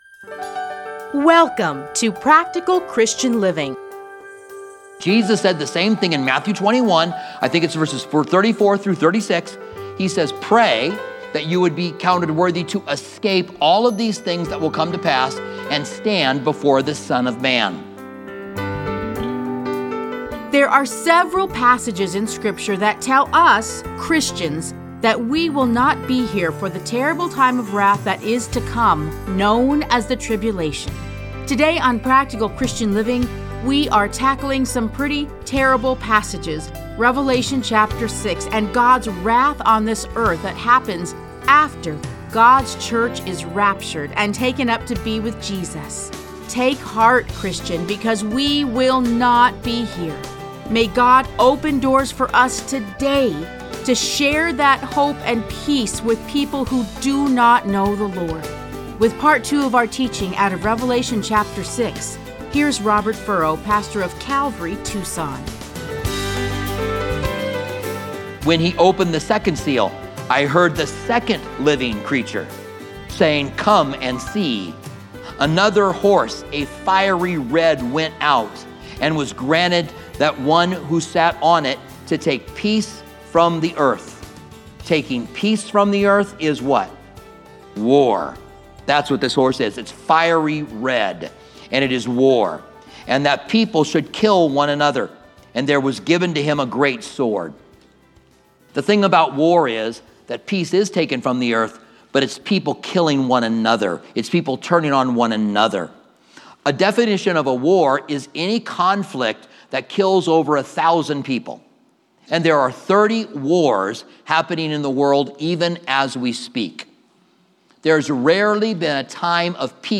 Listen to a teaching from Revelation 6:1-8.